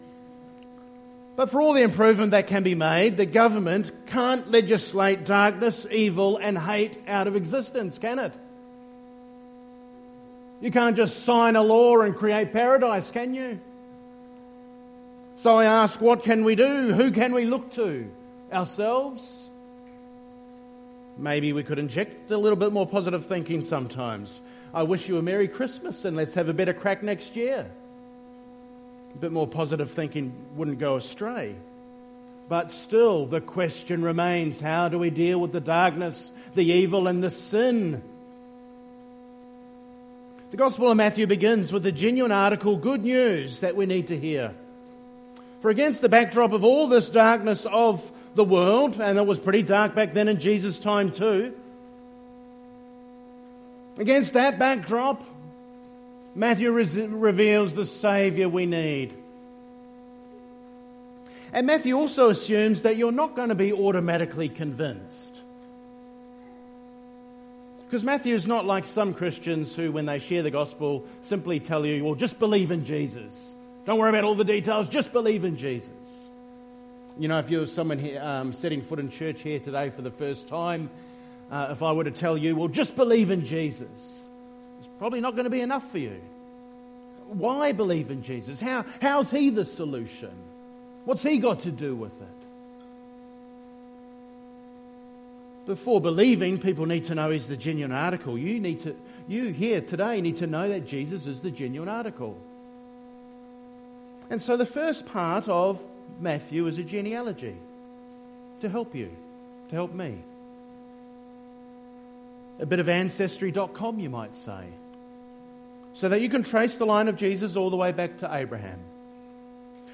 Matt. 1:18-25 – God hasn’t ditched or fallen world- Christmas Service 2025